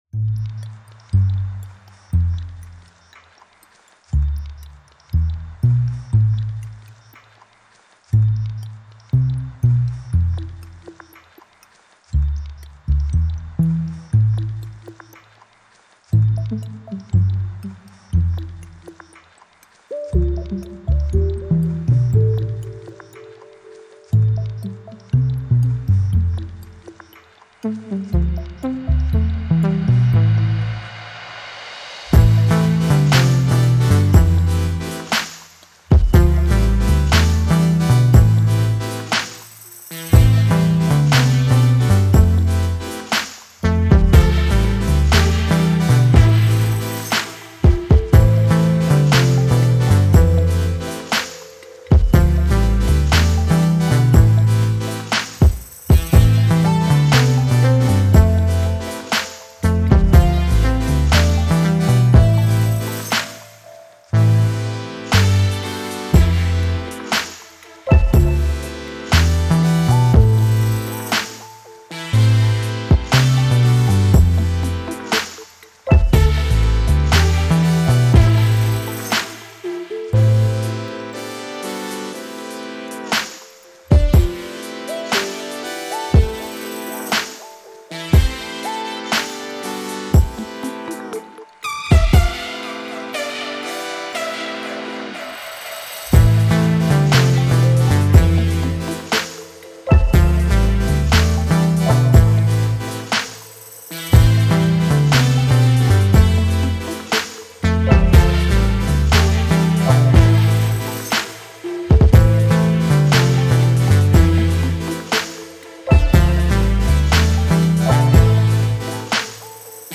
Simple, slow beat with slightly sluggish main synth theme.